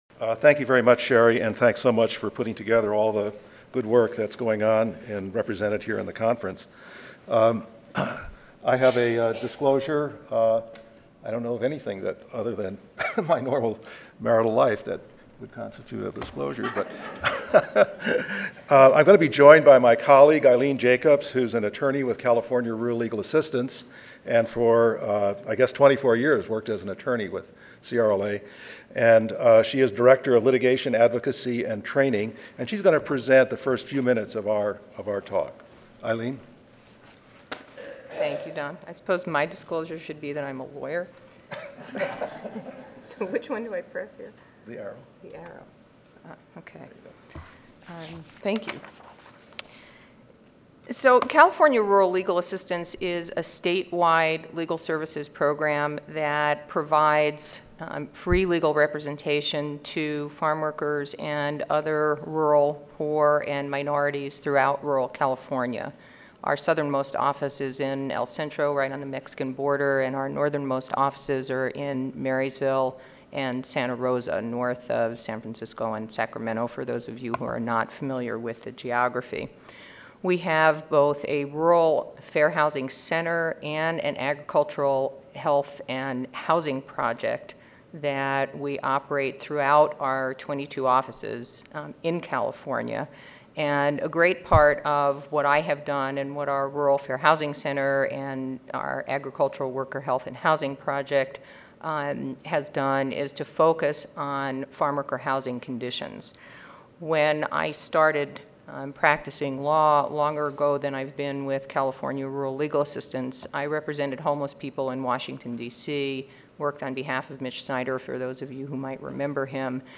4050.0 Occupational Health Disparities Institute: Overview of OHD and Future Directions Tuesday, November 9, 2010: 8:30 AM - 10:00 AM Oral Session Objectives: Describe efforts by OSHA to address the occupational health status of Hispanic workers.